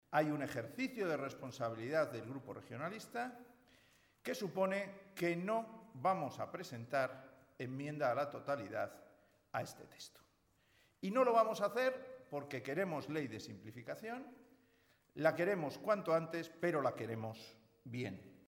Ver declaraciones de Pedro Hernando, portavoz parlamentario del Partido Regionalista de Cantabria.
Pedro Hernando en un momento de la rueda de prensa que ha ofrecido hoy